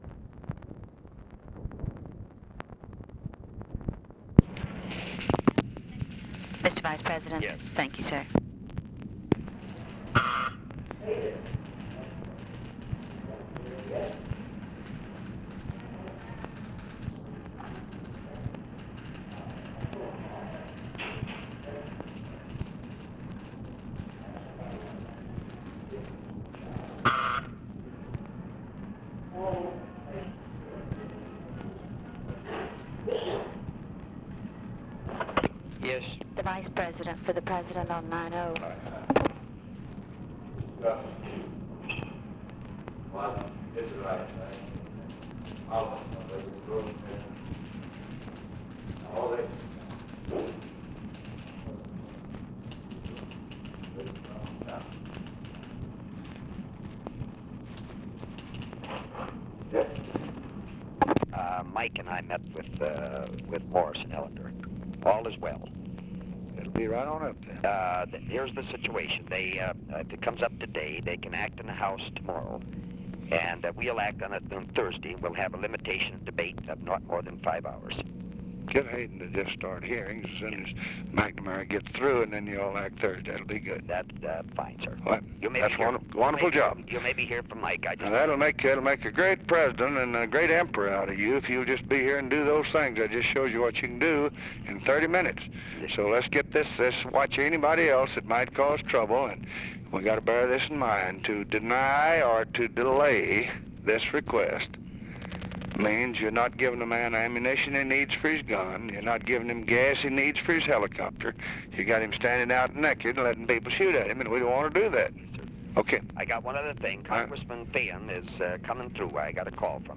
Conversation with HUBERT HUMPHREY and OFFICE CONVERSATION, May 4, 1965
Secret White House Tapes